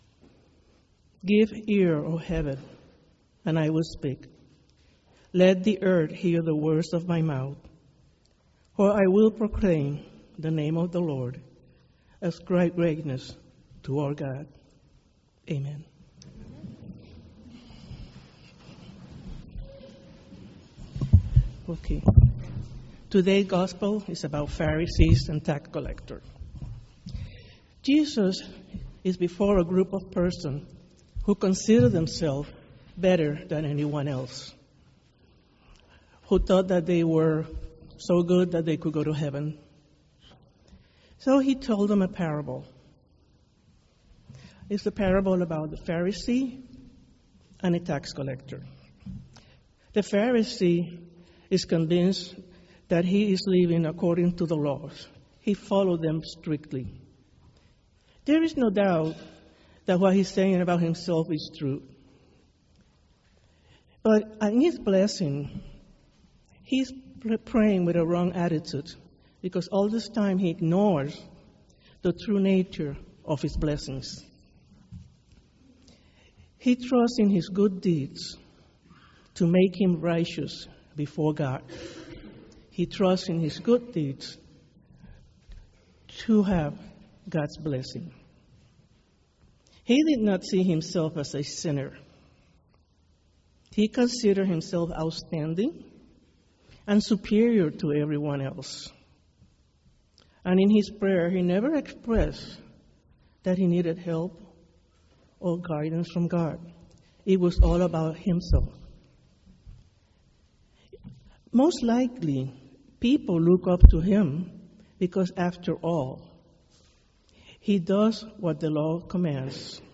Sermon - The 20th Sunday after Pentecost